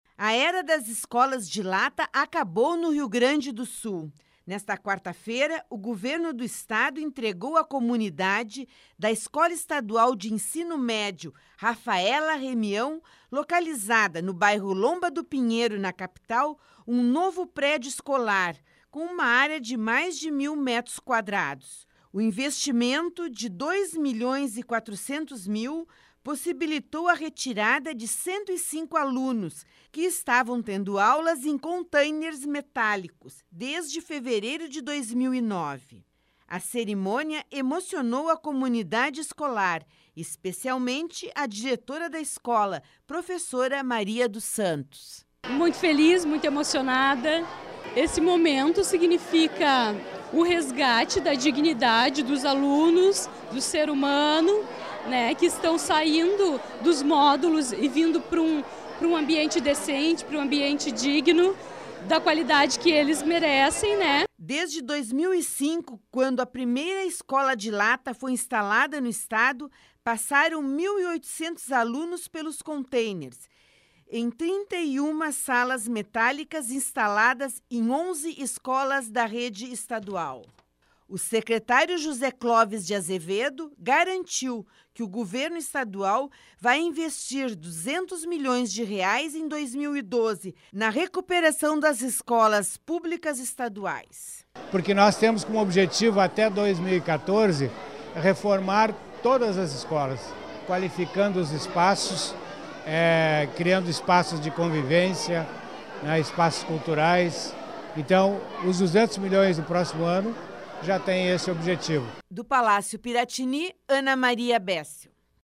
boletim-escolas-de-lata.mp3